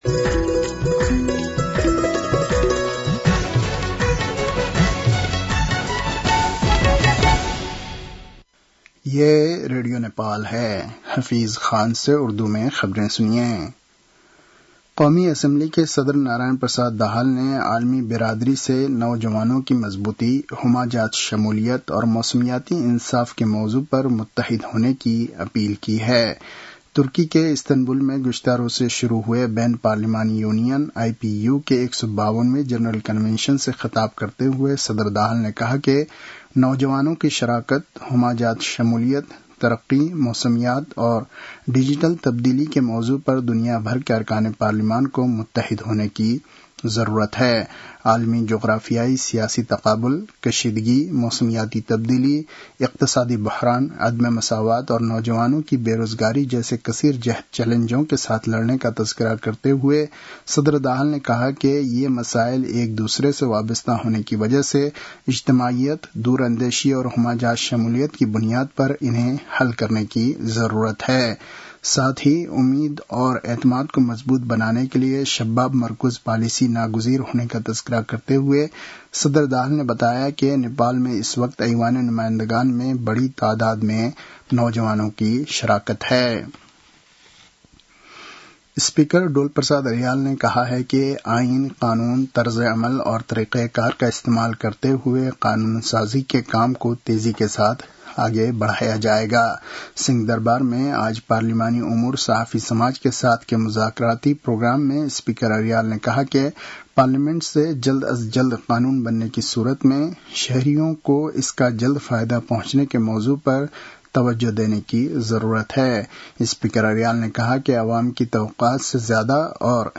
उर्दु भाषामा समाचार : ४ वैशाख , २०८३